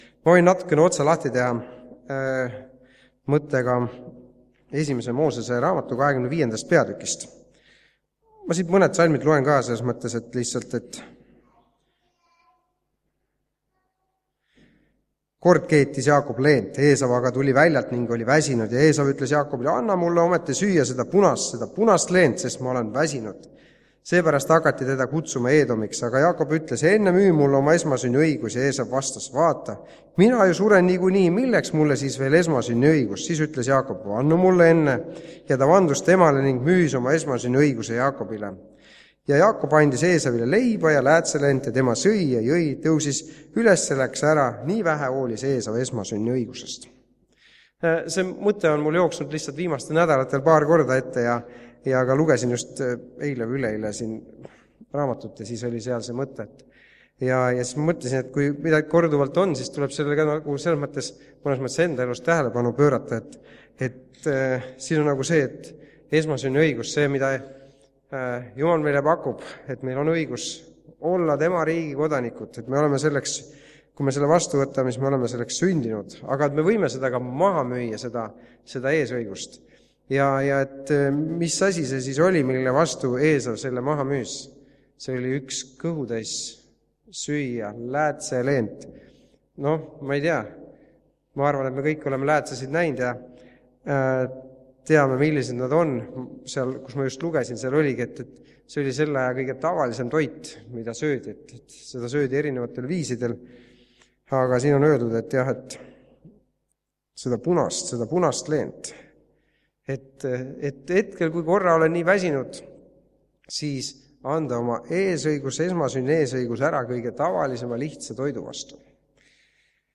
TUNNISTUSTE KOOSOLEK (Haapsalus)
Täna on meil tunnistuste koosolek.